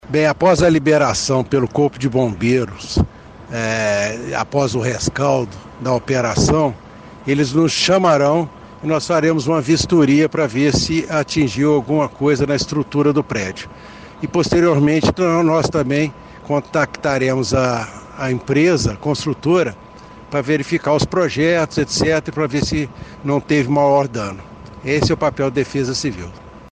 A Defesa Civil chegou ao local e o subsecretário Jefferson Rodrigues falou com a nossa reportagem.
subsecretário da Defesa Civil Jefferson Rodrigues